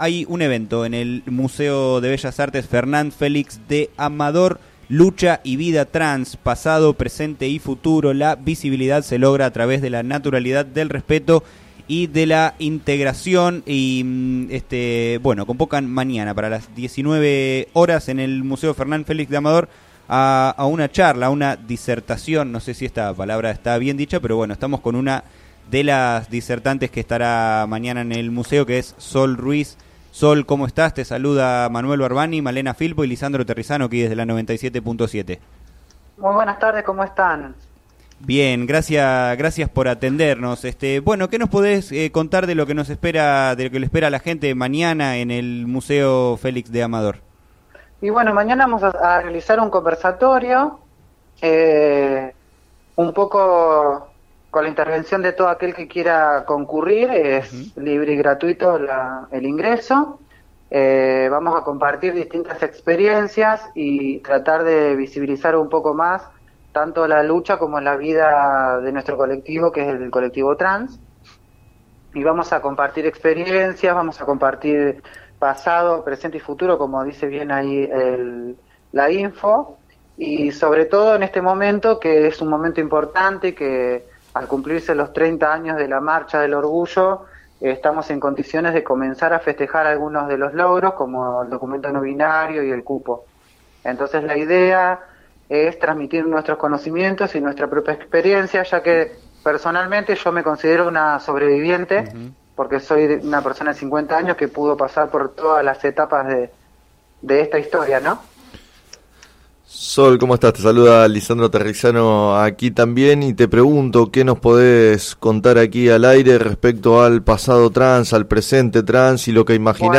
En declaraciones al programa “Sobre las cartas la mesa” de FM Líder 97.7,